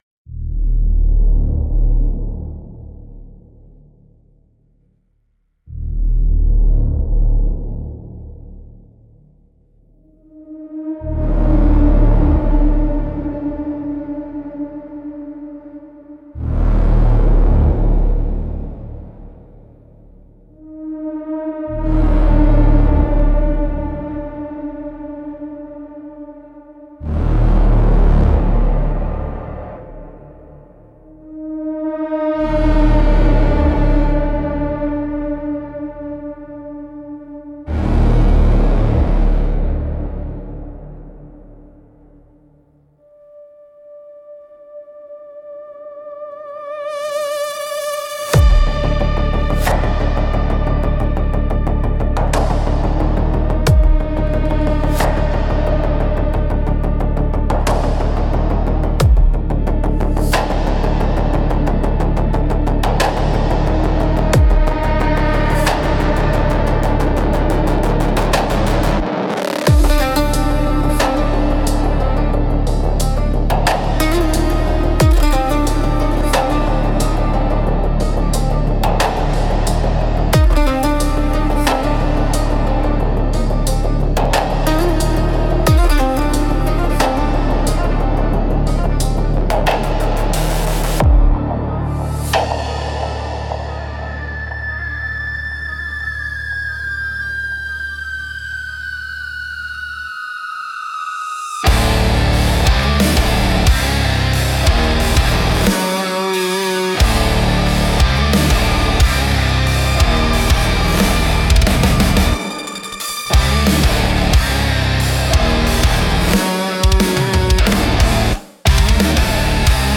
Instrumental - A Phosphor Echo in the Static 4.08